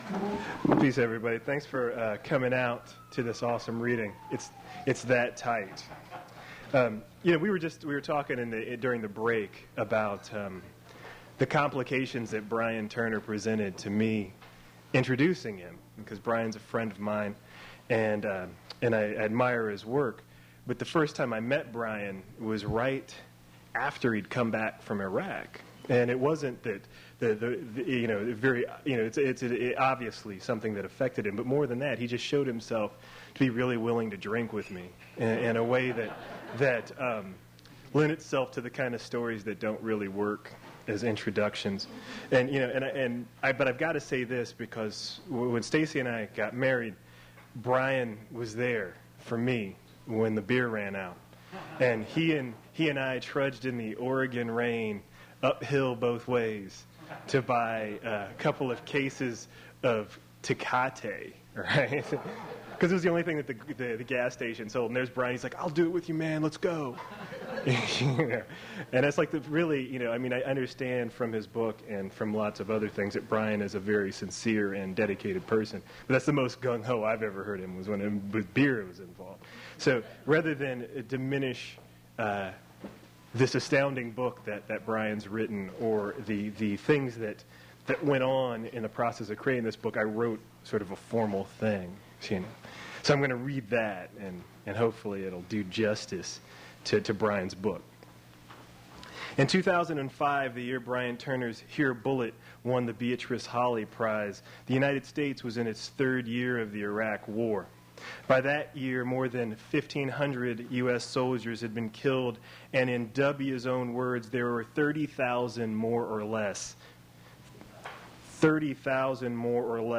Poetry reading featuring Brian Turner
Attributes Attribute Name Values Description Brian Turner poetry reading at Duff's Restaurant.
Source mp3 edited access file was created from unedited access file which was sourced from preservation WAV file that was generated from original audio cassette.
Note this audio also cuts off abrubtly in the mddle of the last poem